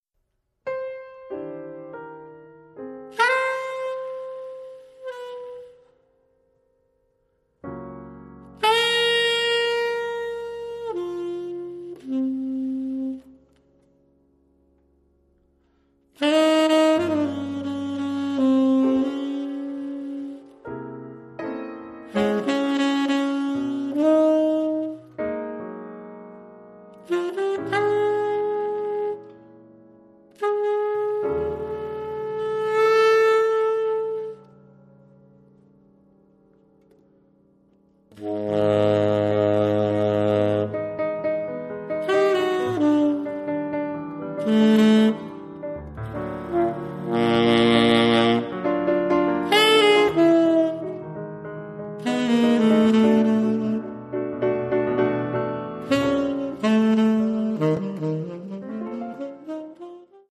pianoforte
sax tenore